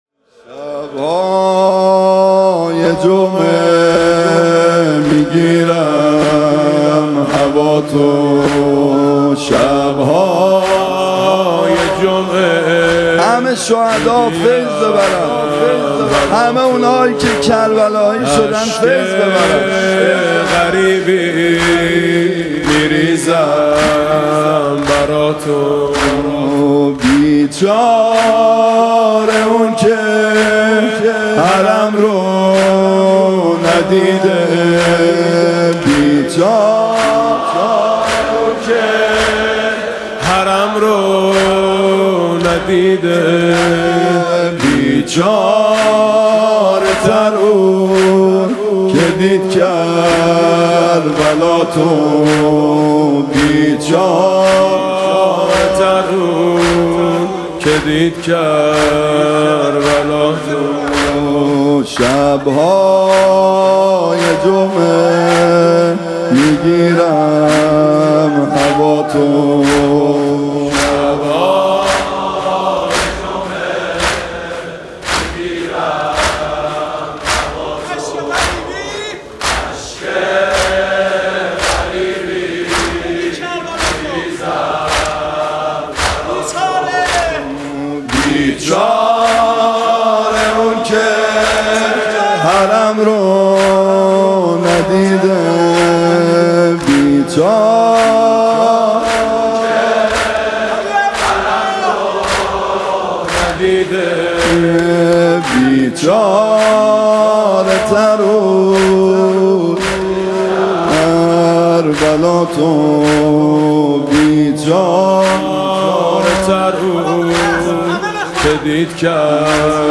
[آستان مقدس امامزاده قاضي الصابر (ع)]
عنوان: شب شهادت حضرت زهرا (س)